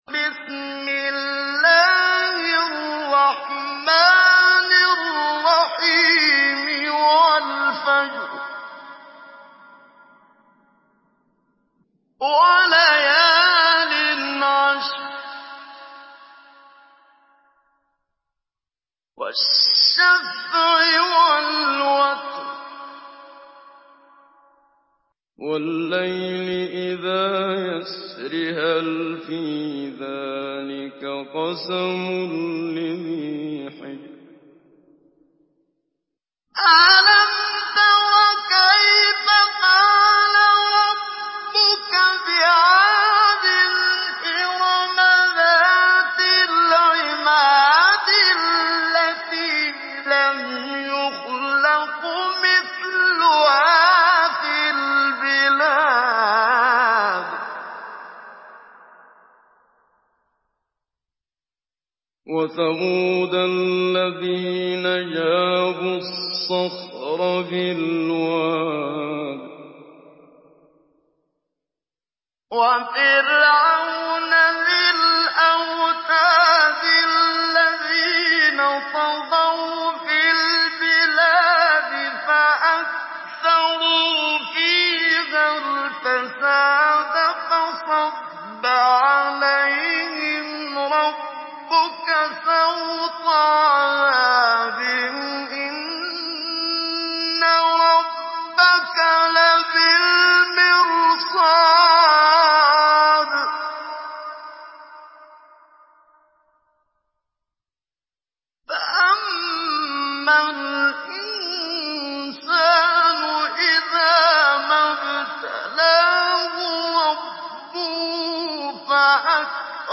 Surah আল-ফাজর MP3 in the Voice of Muhammad Siddiq Minshawi Mujawwad in Hafs Narration
Surah আল-ফাজর MP3 by Muhammad Siddiq Minshawi Mujawwad in Hafs An Asim narration.